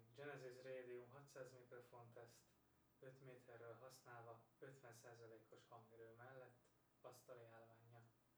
Hangminőség teszt: távolság tesztek